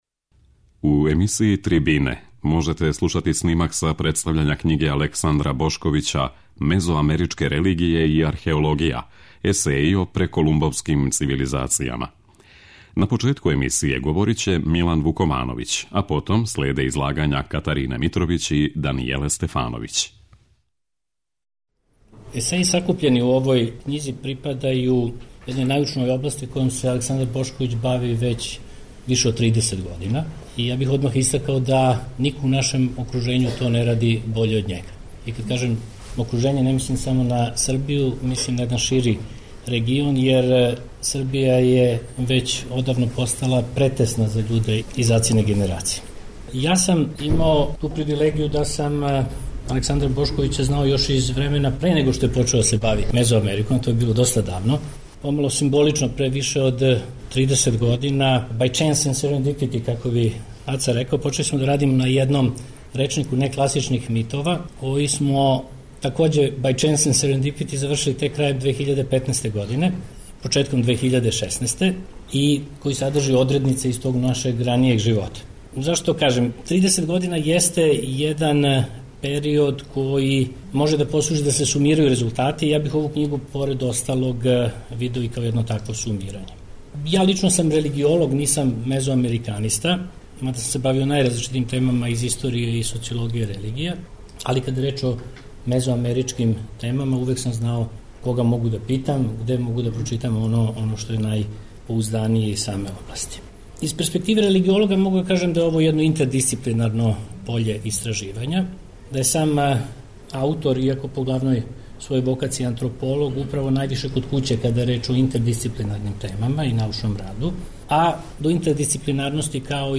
Снимак је забележен 13. априла на Филозофском факултету у Београду.